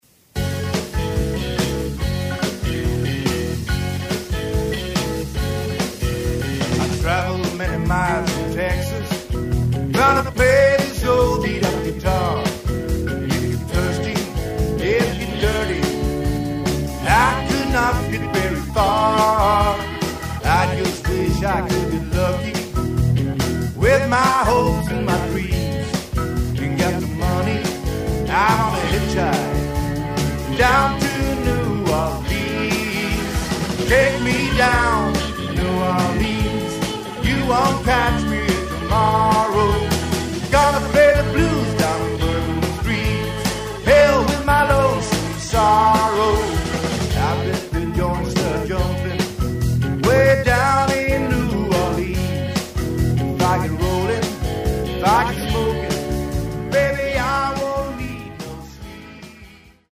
Gitarr/Sång
Piano/Orgel/Dragspel/Sång
Trummor